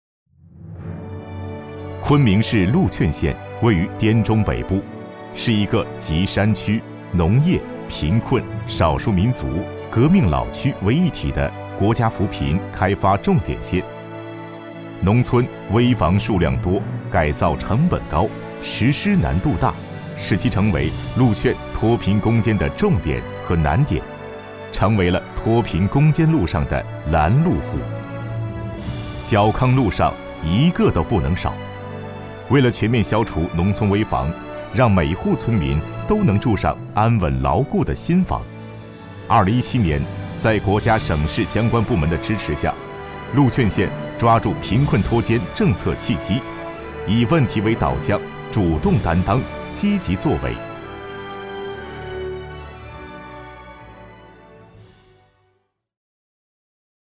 男国317_专题_政府_危房改造_浑厚.mp3